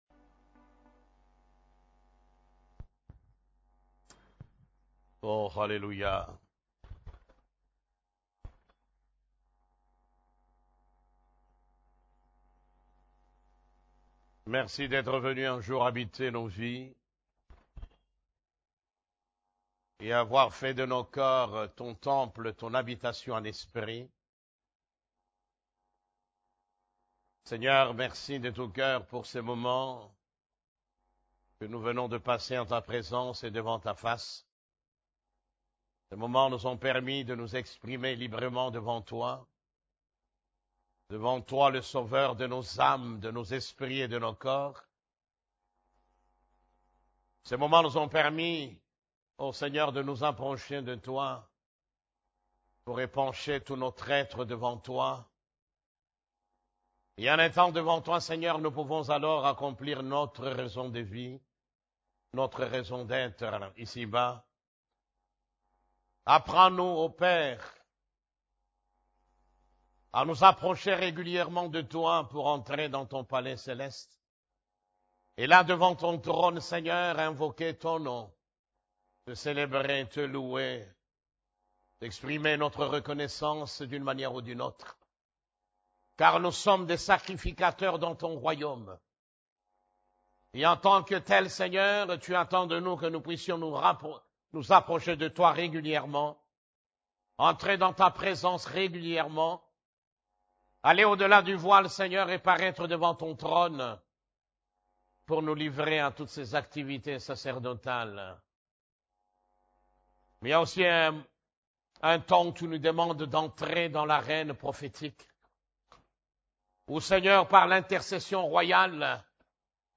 CEF la Borne, Culte du Dimanche, Comment voir l'invisible ? (5)